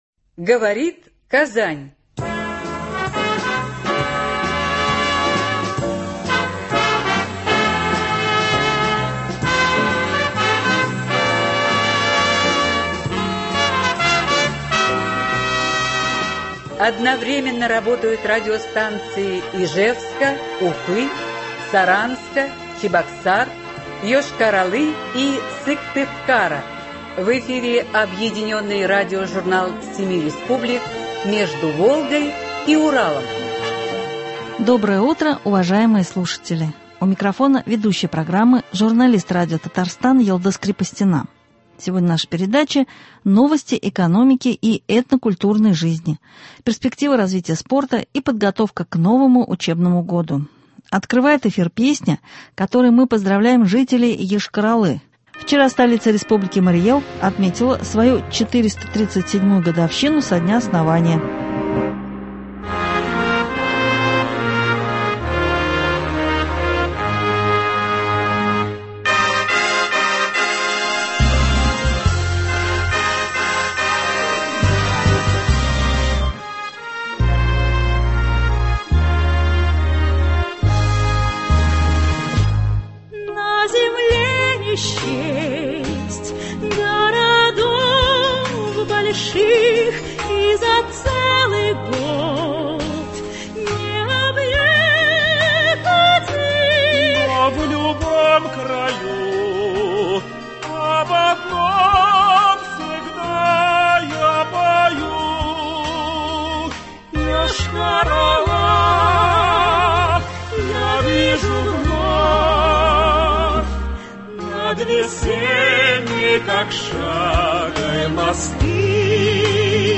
Объединенный радиожурнал семи республик.
Сегодня в программе – новости экономики и этнокультурной жизни, перспективы развития спорта и подготовка к новому учебному году. Открывает эфир песня, которой мы поздравляем жителей Йошкар-Олы — вчера столица Республики Марий Эл отметила свою 437 годовщину со дня основания.